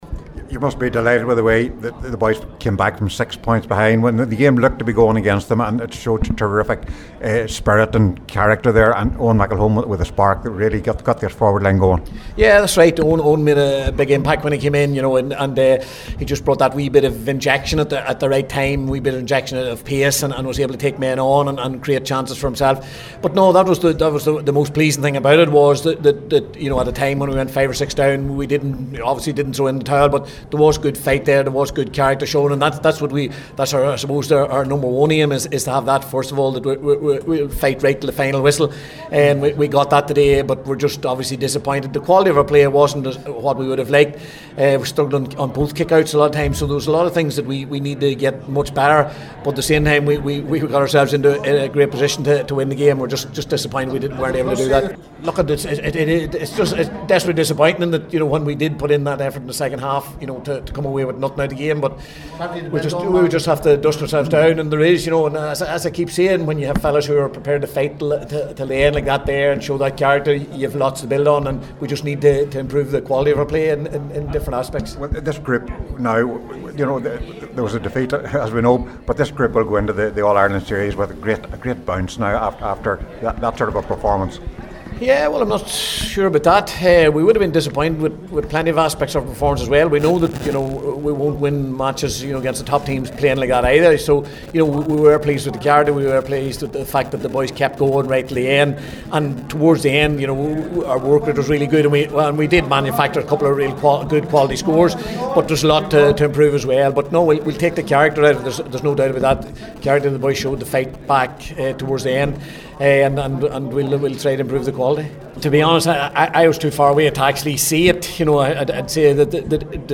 spoke to the assembled media